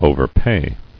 [o·ver·pay]